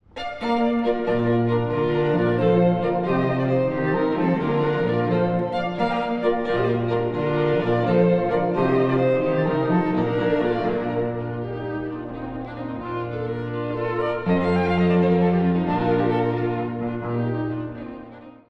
冒頭のフレーズは「狩りの角笛」を思わせる力強さ。
第1楽章｜冒頭から一気に駆け出す
古い音源なので聴きづらいかもしれません！（以下同様）
8分の6拍子で、勢いよく駆け出す冒頭が印象的。